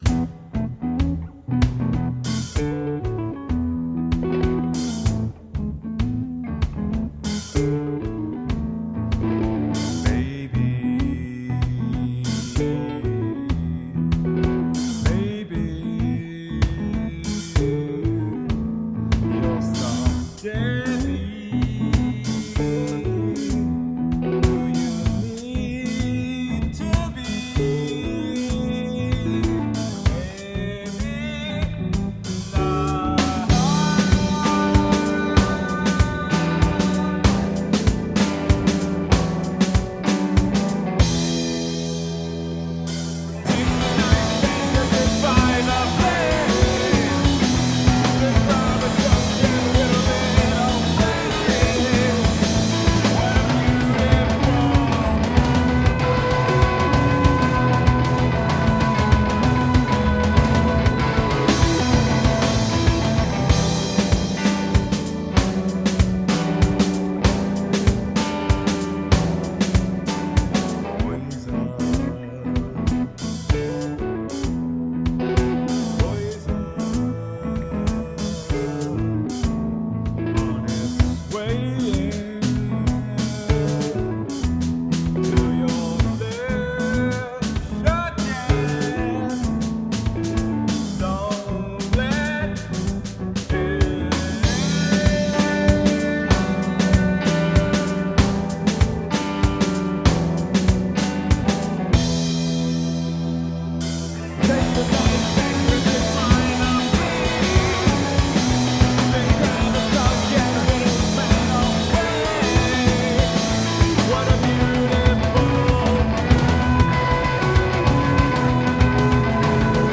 The schizo rock will still be here.